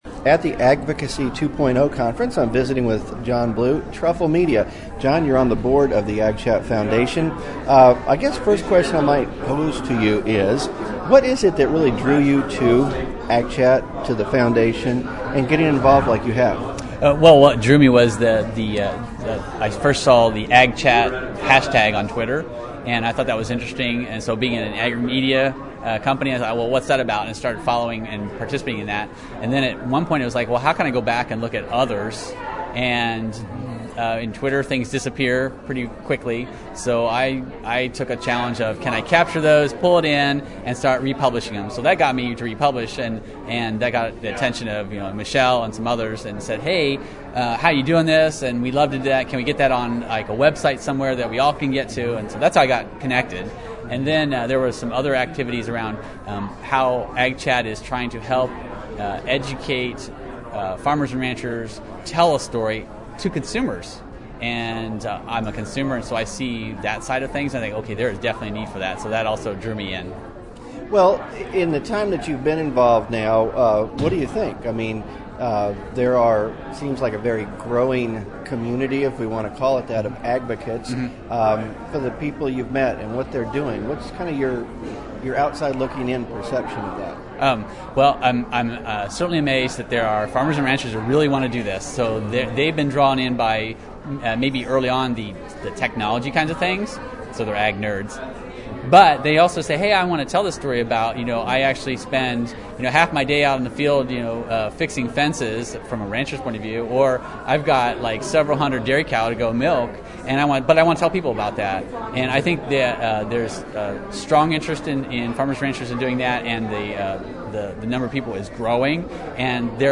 2011 AgChat Agvocacy 2.0 Conference Photo Album